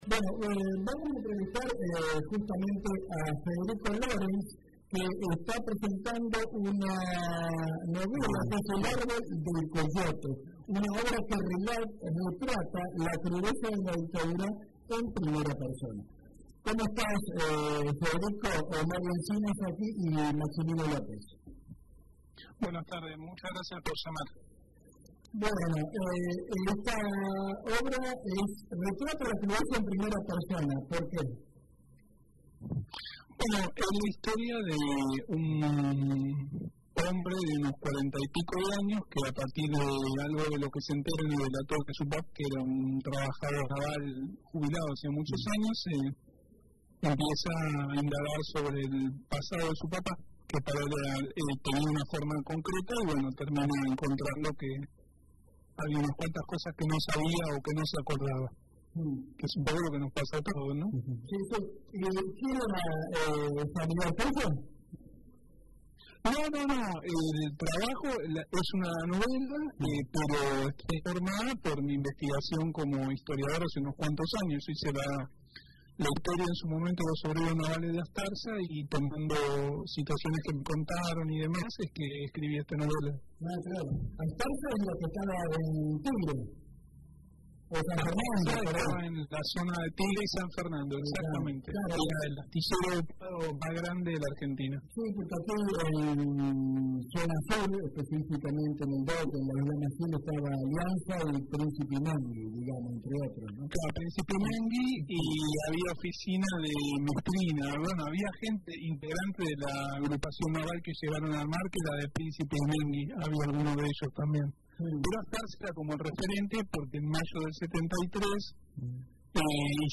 Federico Lorenz en Historias de Aquí Texto de la nota: Federico Lorenz pasó por Radio UNDAV En esta edición, conversamos con el historiador y escritor Federico Lorenz sobre su novela El árbol de Coyote , una obra que recupera el pasado obrero a partir de la historia de un hombre que decide investigar la vida de su padre y descubre aspectos desconocidos de su historia personal y colectiva. Durante la entrevista, reflexionó sobre la dictadura, la guerra de Malvinas y sus consecuencias en la sociedad argentina, poniendo el foco en la transformación de la cultura de la clase trabajadora y en los lazos de solidaridad que fueron sistemáticamente atacados.
Una charla que cruza memoria, política y literatura, e invita a reflexionar sobre qué pasado reconstruimos y qué futuro queremos construir.